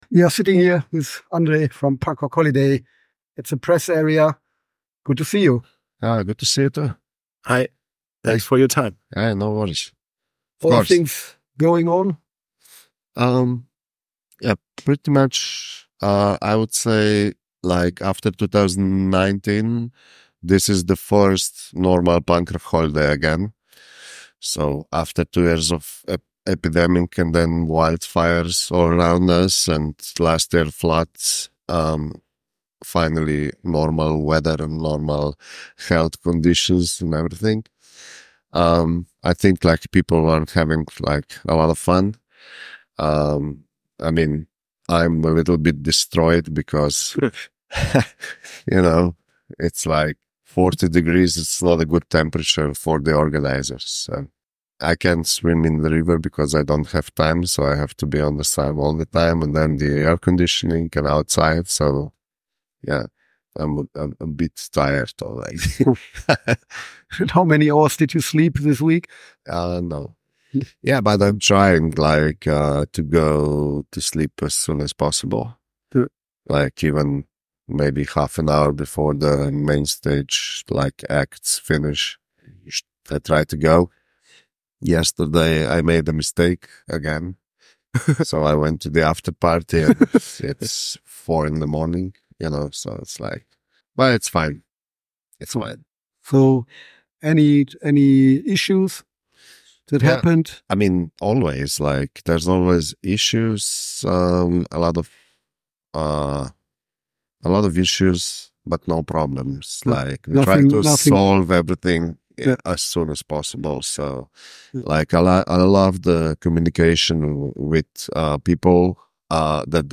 Letzte Episode Interview @ Punk Rock Holiday 2.4